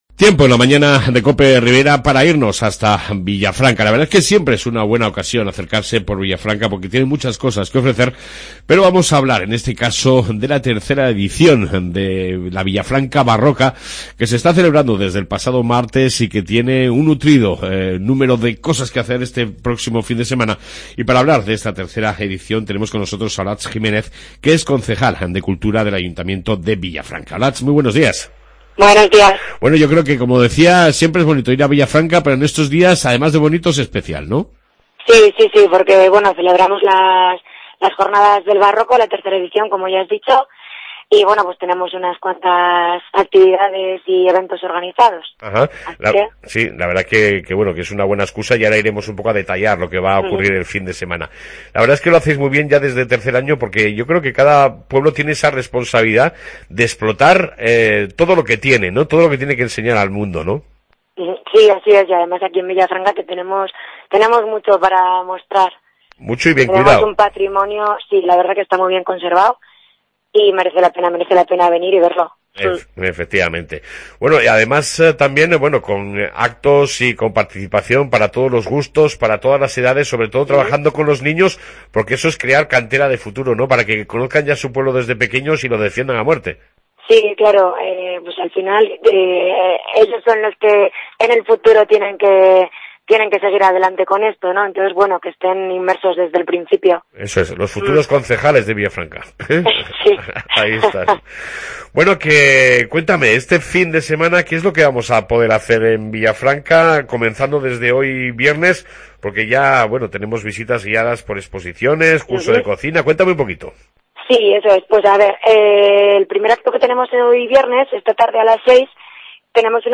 AUDIO: Entrevista con la concejal de cultura de Villafranca, Olatz Jimenez, sobre las jornadas del Barroco